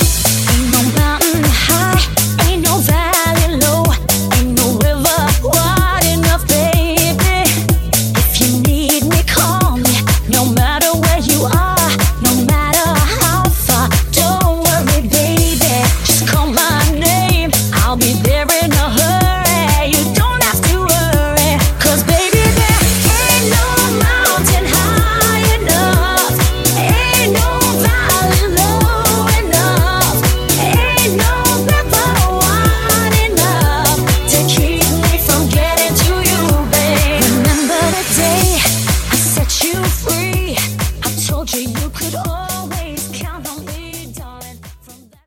Genre: 2000's
Dirty BPM: 95 Time